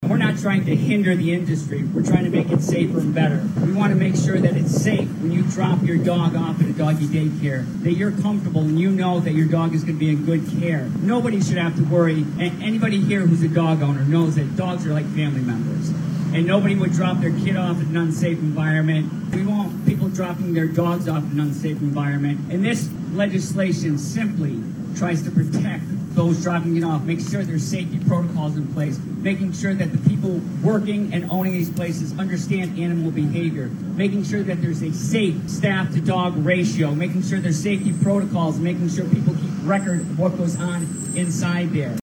A rally was held in front of the State House on Wednesday in support of Ollie’s Law which calls for statewide standards on the so-called “doggie day care industry” in Massachusetts.